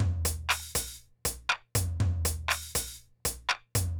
Drumloop 120bpm 10-B.wav